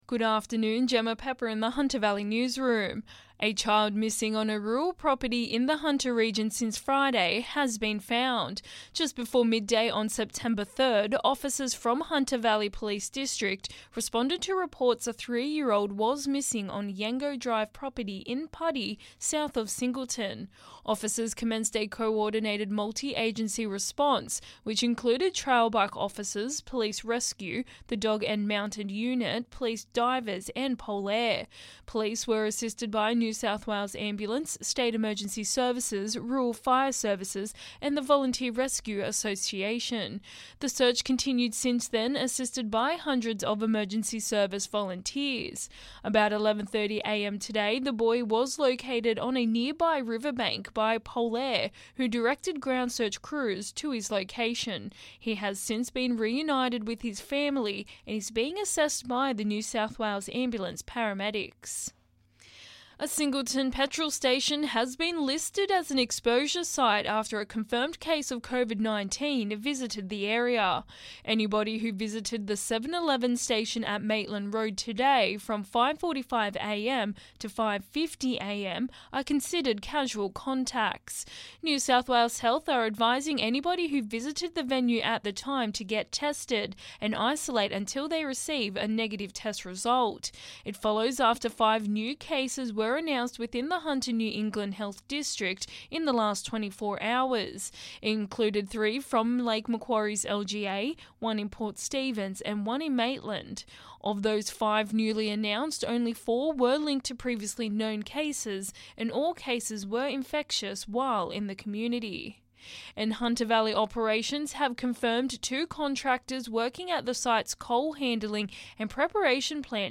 Listen: Hunter Local News Headlines 06/09/2021